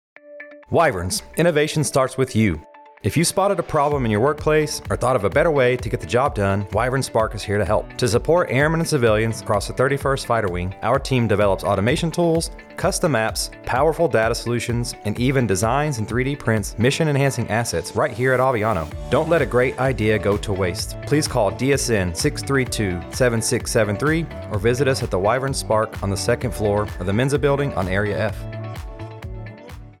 AFN Aviano Radio Spot: Wyvern Spark